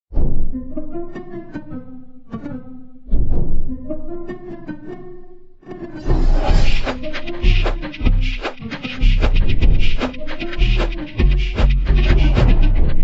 among-us-made-with-Voicemod-technology-timestrech-1.62x.mp3